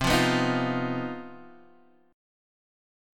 CmM7bb5 chord